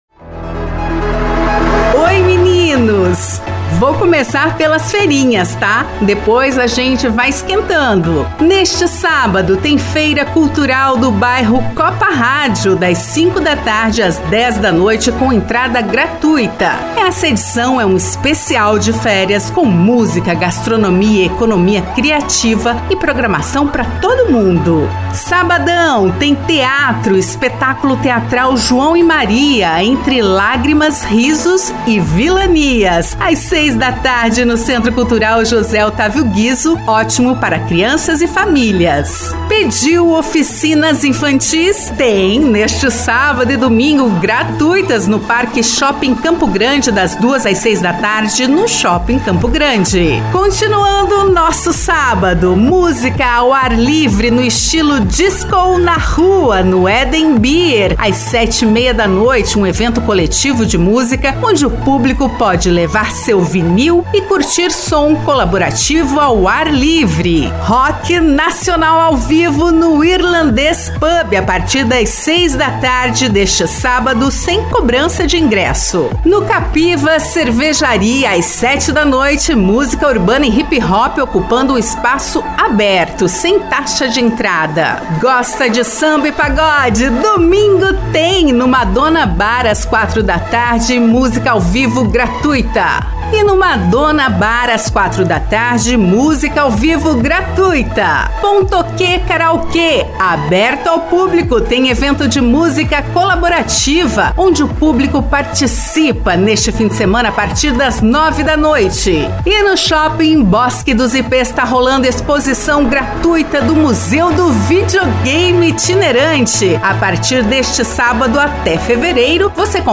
A repórter